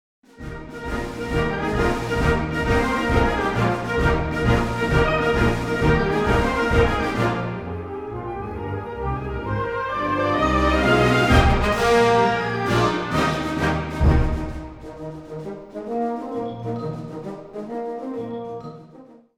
Category Concert/wind/brass band
Instrumentation Ha (concert/wind band)